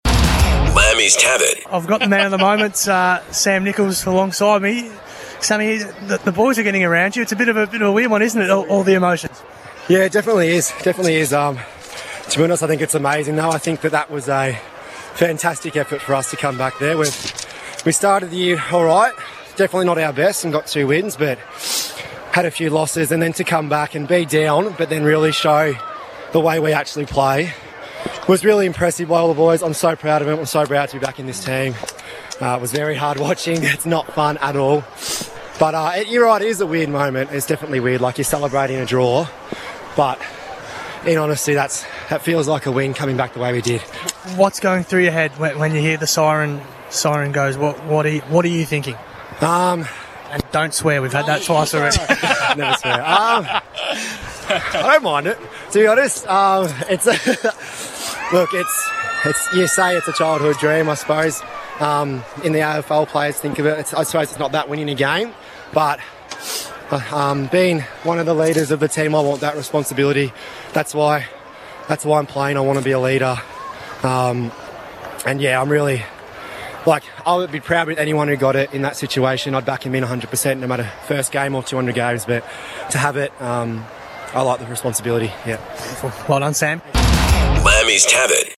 POST-MATCH INTERVIEW